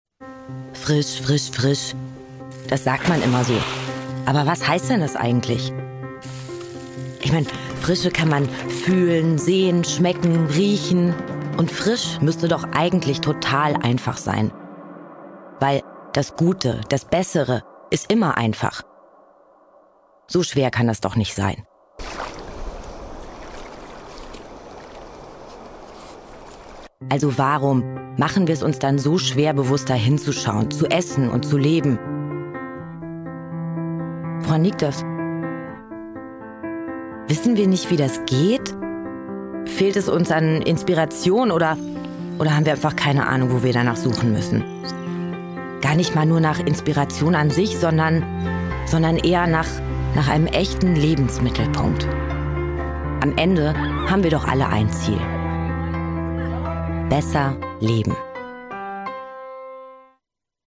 dunkel, sonor, souverän, sehr variabel
Mittel minus (25-45)
Commercial (Werbung)